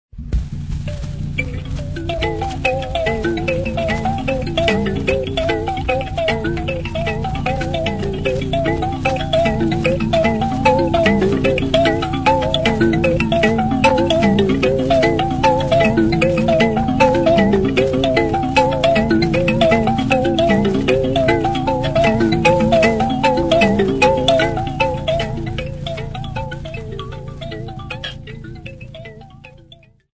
Folk Music
Field recordings
Africa, Sub-Saharan
sound recording-musical
Indigenous music